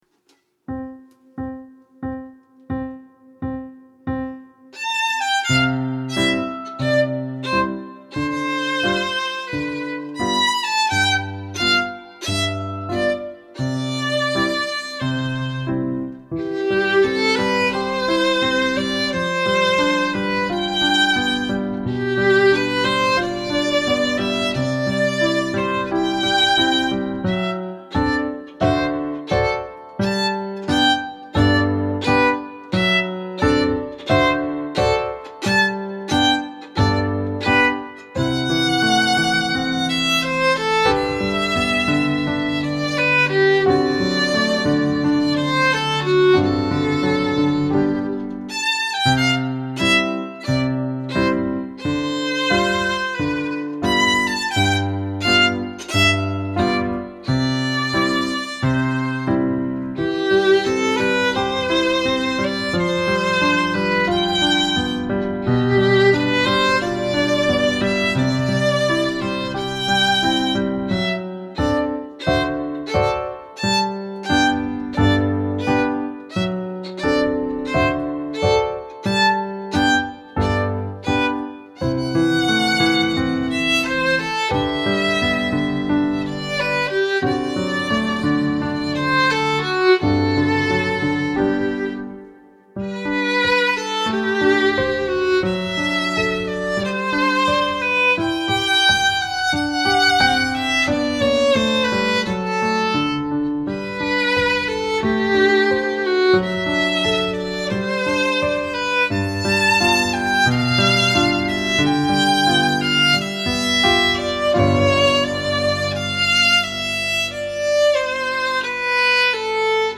Instrumentation: Violin and Piano Accompaniment
A short little piece by French composer